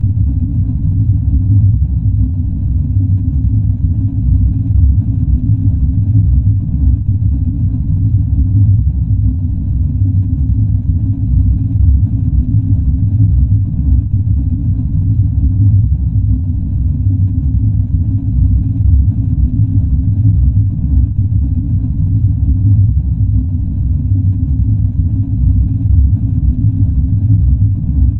ホラー（心理）
迫ってくる感じ
it_is_coming.mp3